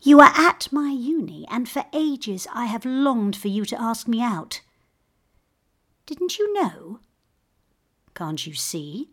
‘Touch’ and listen to the emotions and thoughts of this heartbroken woman.